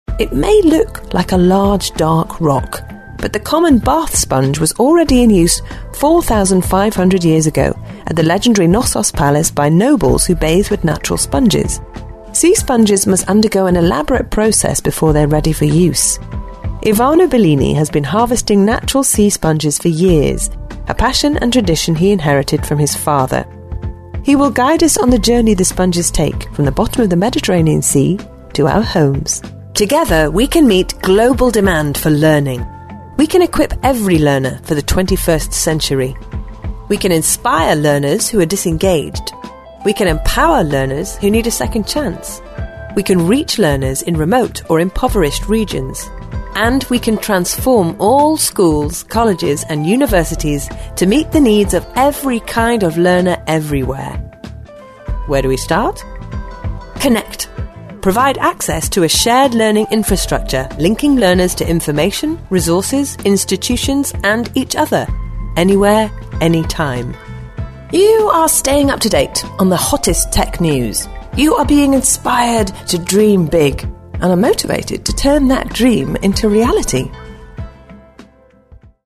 Voice Bank